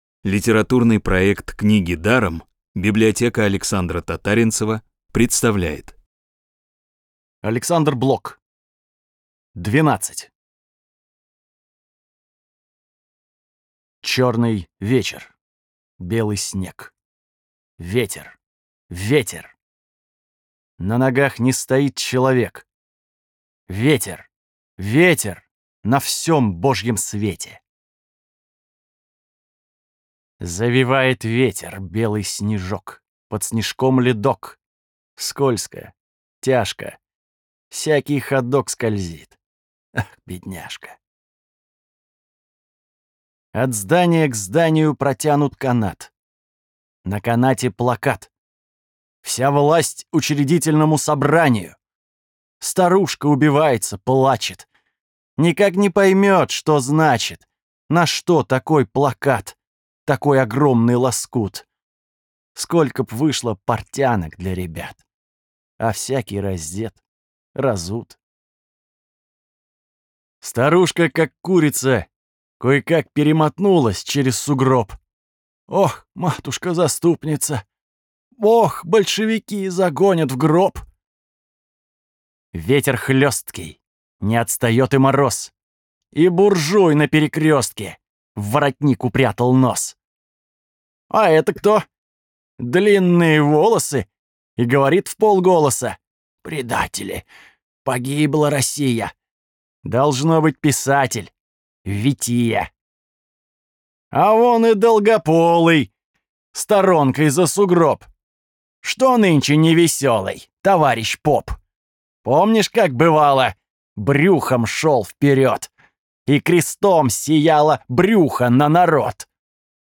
Бесплатная аудиокнига «Двенадцать» от Рексквер.